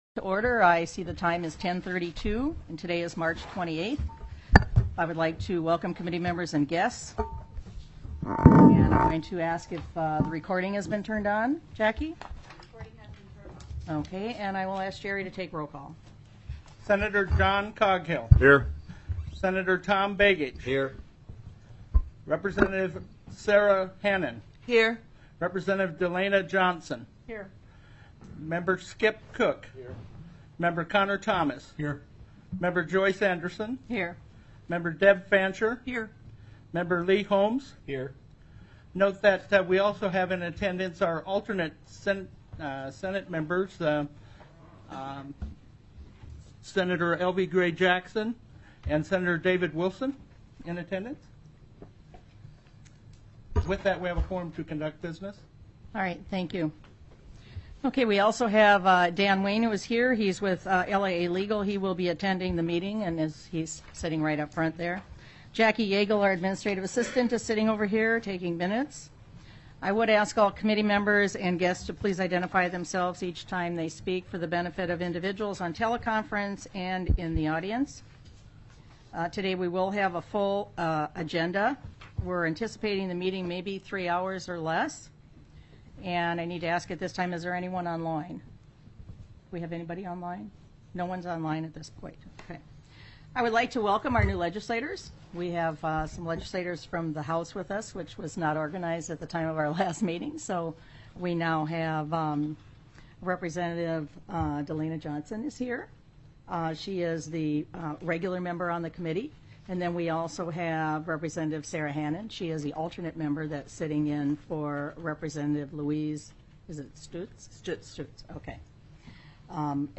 03/28/2019 10:30 AM Senate SELECT COMMITTEE ON LEGISLATIVE ETHICS
The audio recordings are captured by our records offices as the official record of the meeting and will have more accurate timestamps.